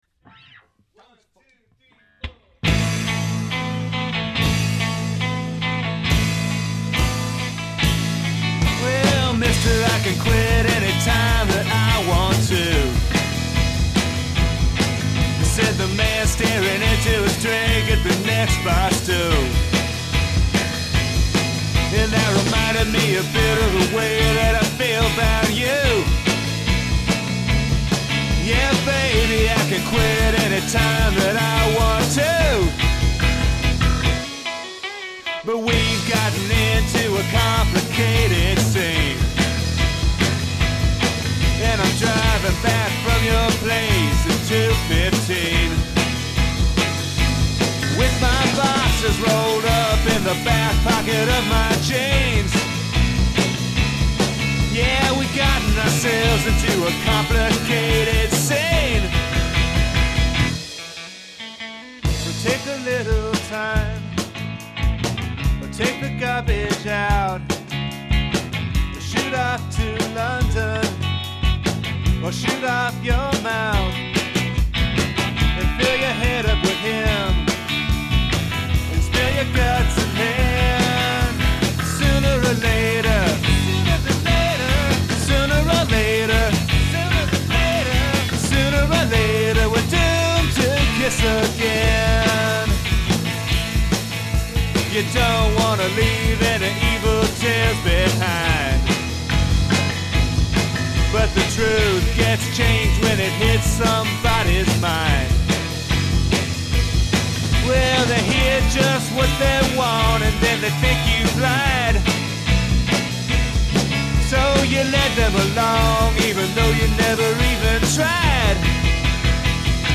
Basement recordings from 2000
drums